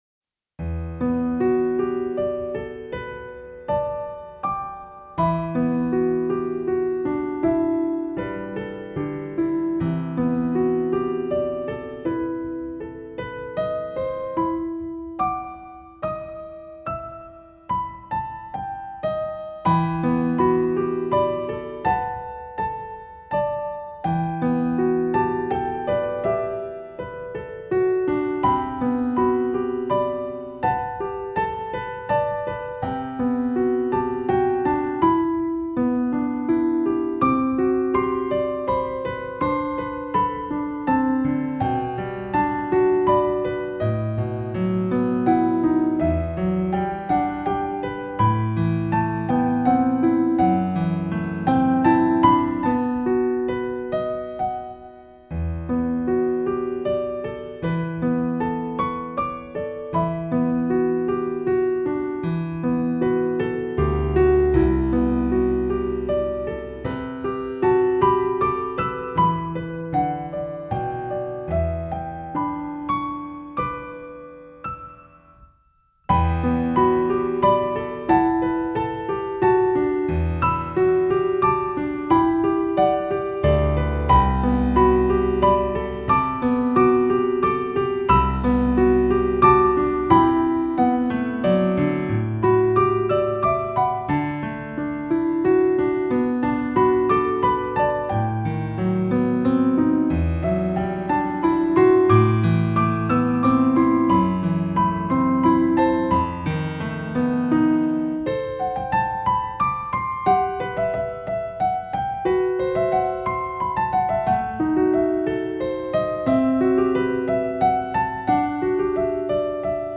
موسیقی اینسترومنتال موسیقی بی کلام
Anime OST